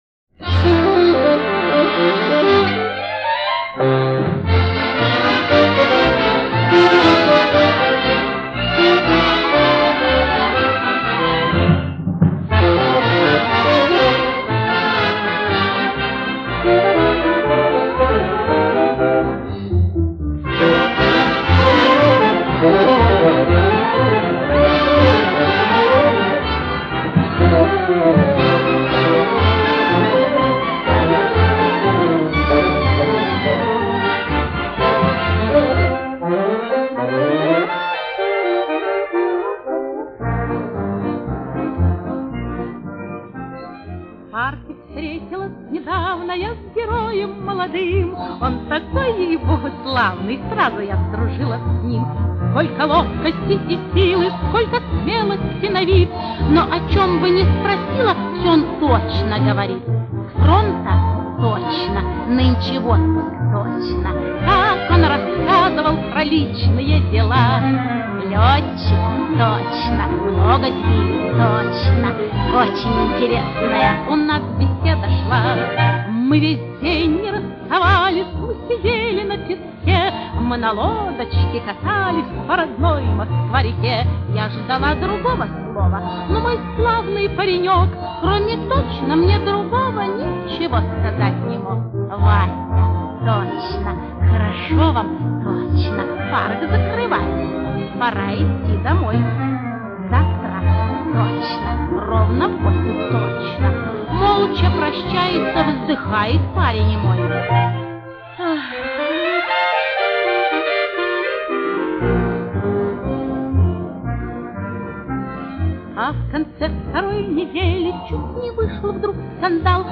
Неподражаемое душевное пение..... мне нравится очень.